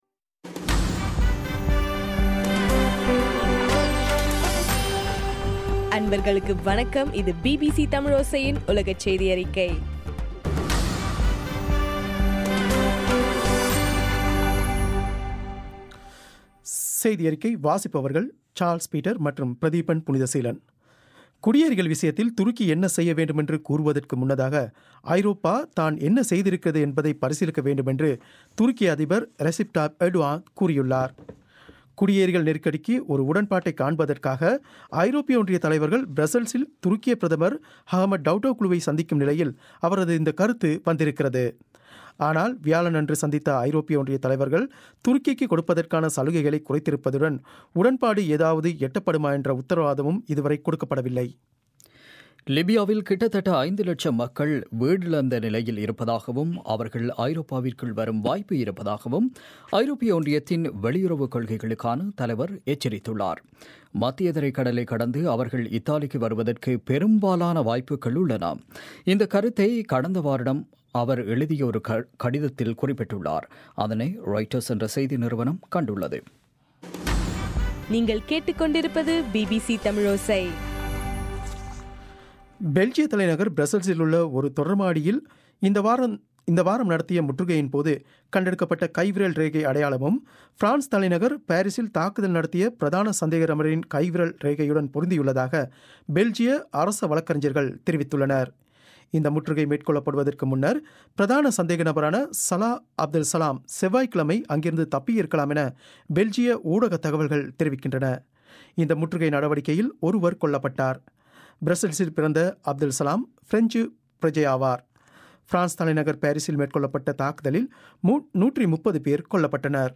பிபிசி தமிழோசை- உலகச் செய்தியறிக்கை- மார்ச் 18